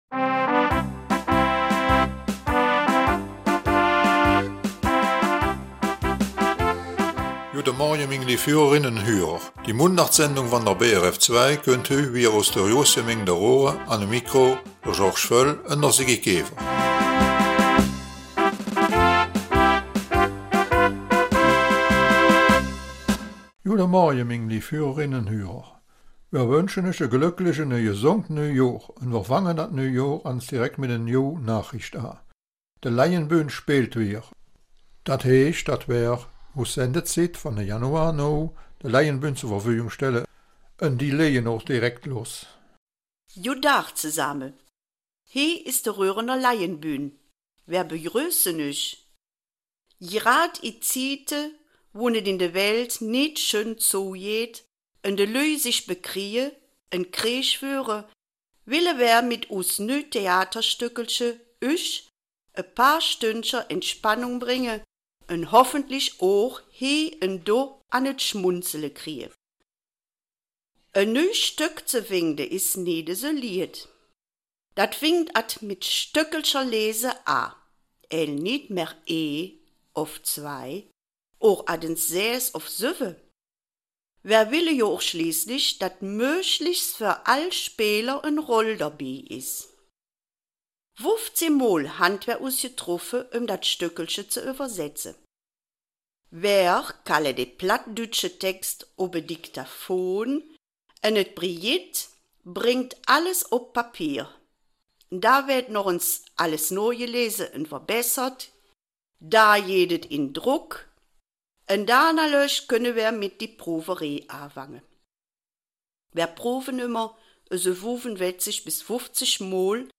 Die Raerener Laienbühne stellt ihr neues Theaterstück: ''Et hölze hellig Odilche'' (die hölzerne heilige Odilia). Dazu gibt es einen geschichtlichen Hintergrund zur Reliquie der heiligen Odilia und wir hören einige Minuten in eine Probe rein.